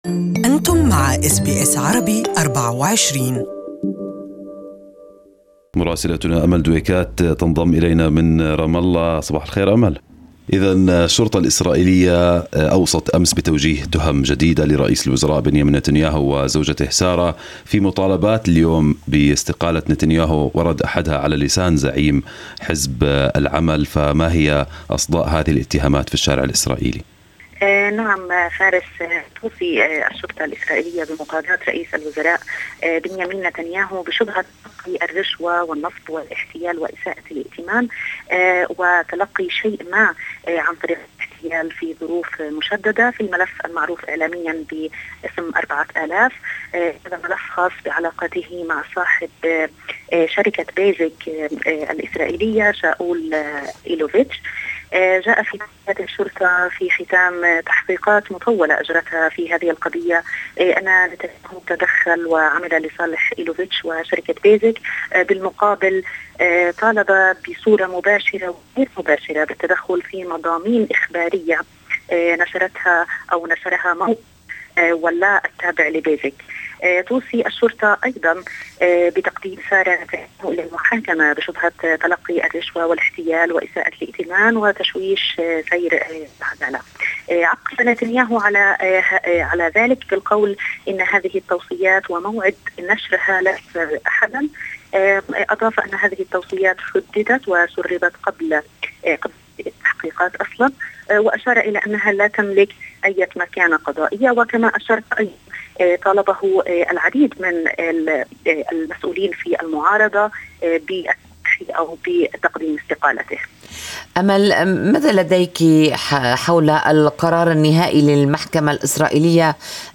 Our correspondent in Ramallah has the details